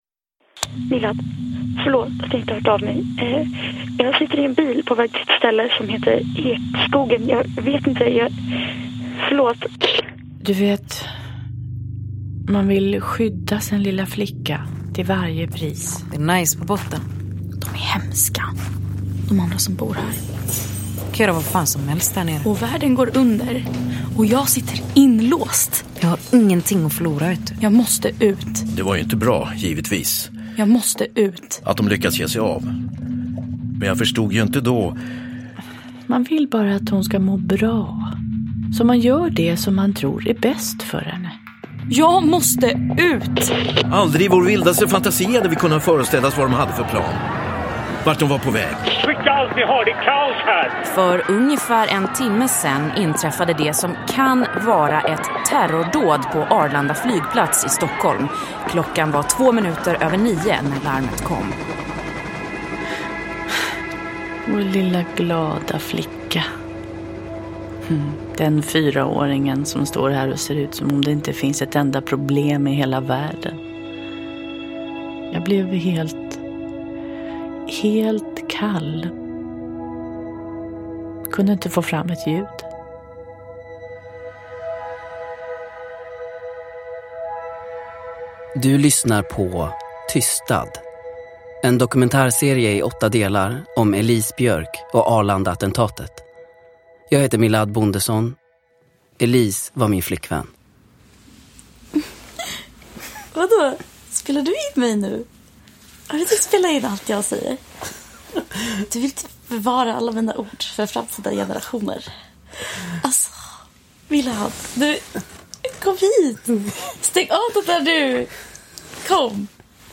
Tystad - 1 – Ljudbok – Laddas ner